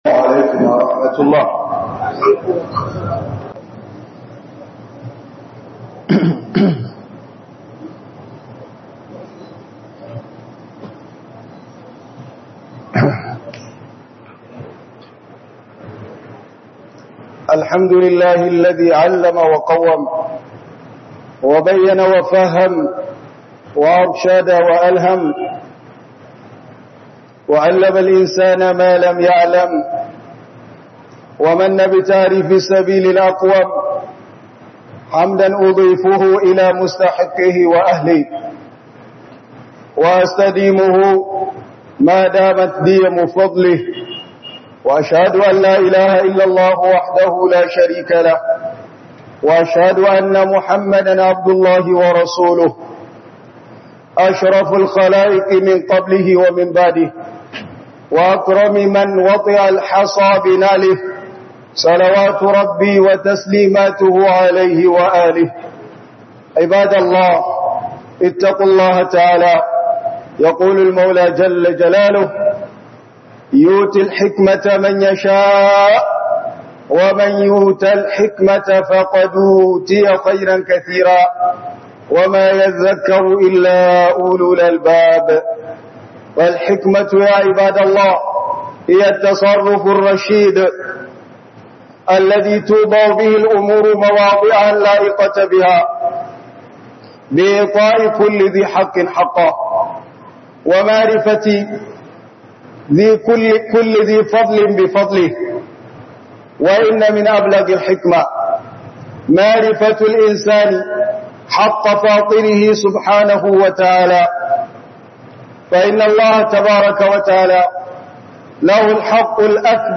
hudubar juma'a 26 Septembre 2025